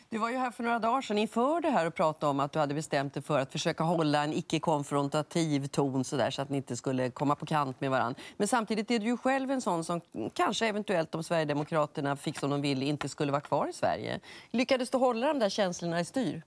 I morse intervjuades Navid Modiri i SVT:s Go’morron Sverige och anledningen var gårdagens golfmatch med Jimmie Åkesson.